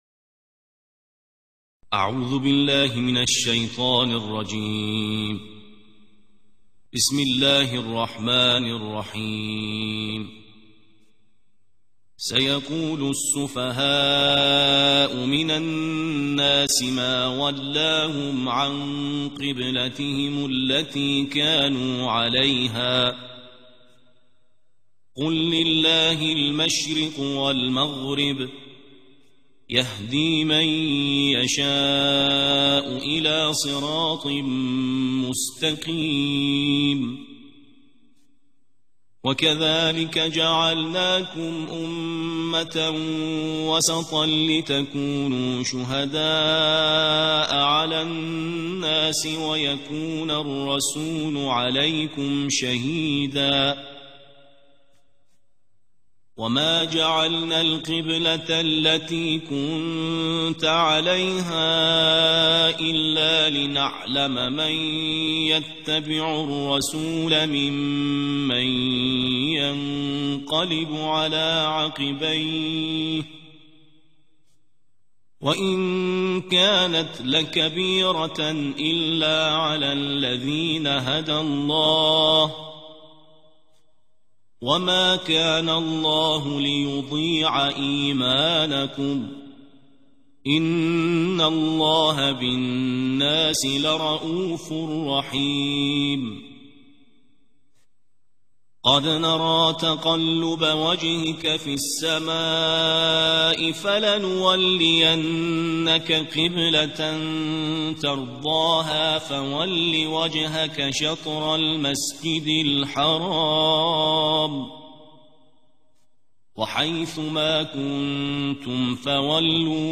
ترتیل جزء دوم